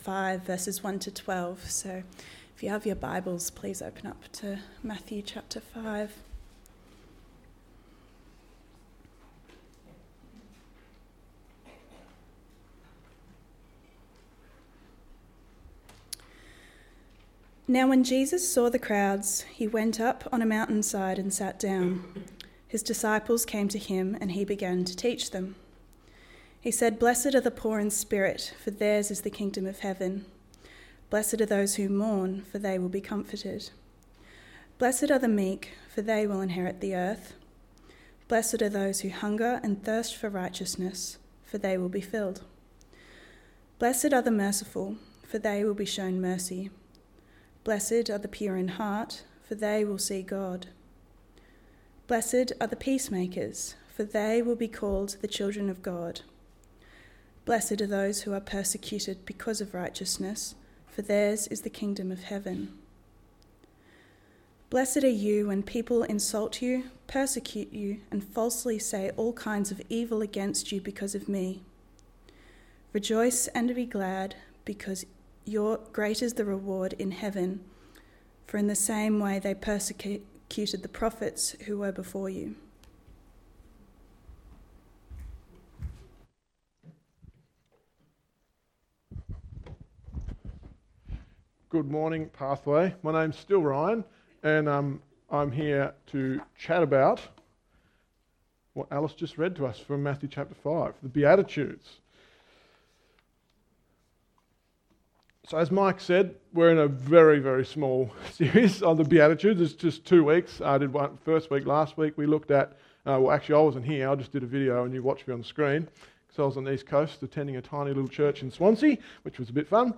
Sermon Series: The Beatitudes